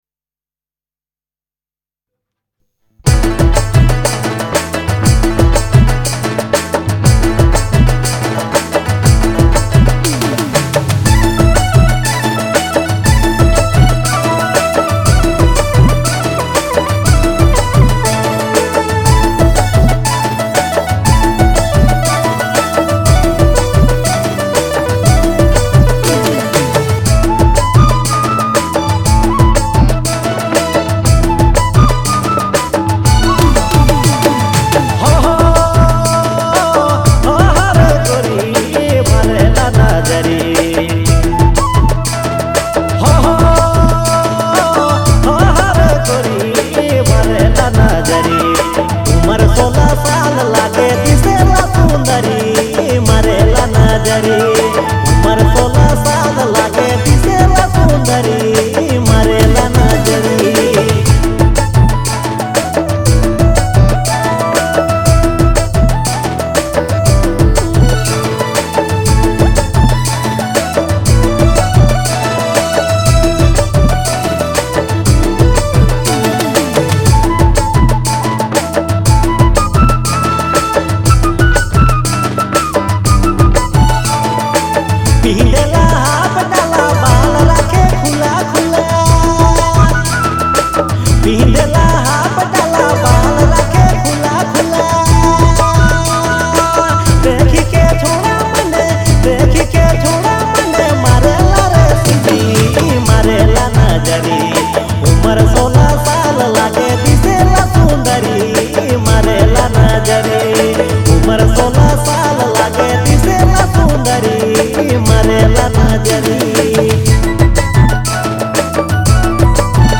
catchy and vibrant Nagpuri song